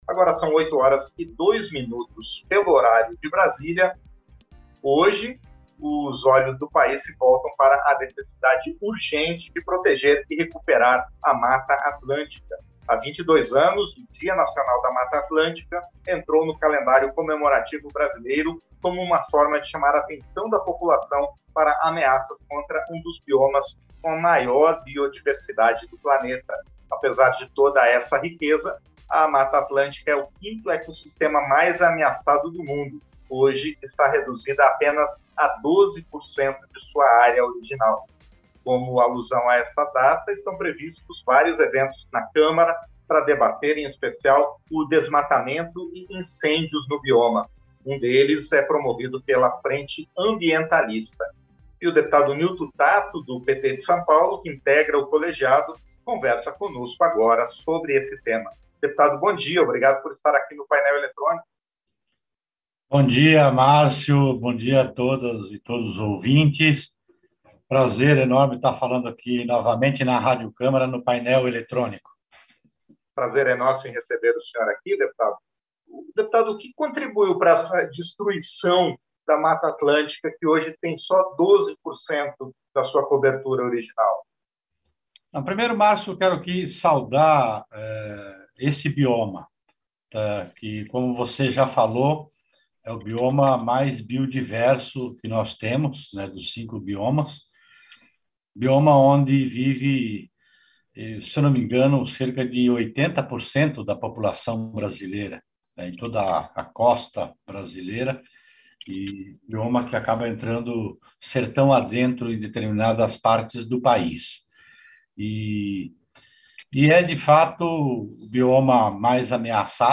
Entrevista - Dep. Nilto Tatto (PT-SP)